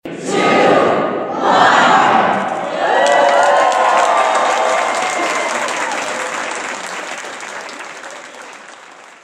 Governor Kristi Noem told a little about her young guest who pushed the button to turn on the display.